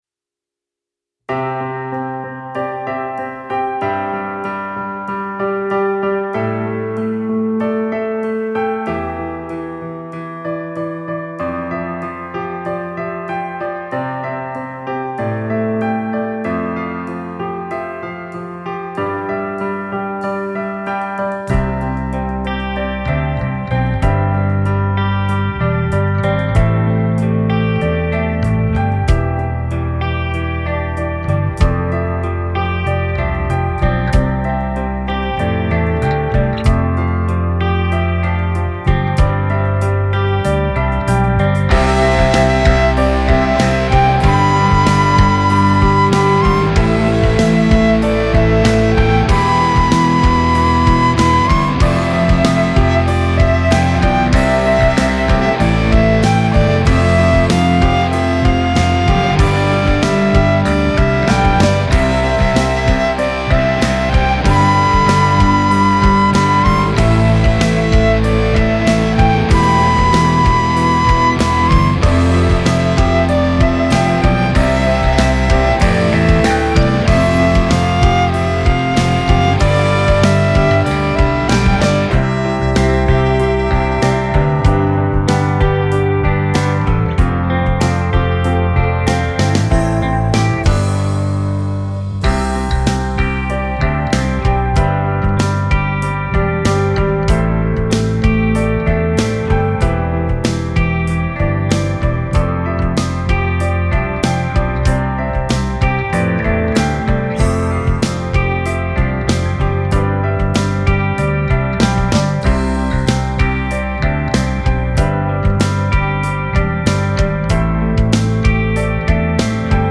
a Singaporean instrumental band.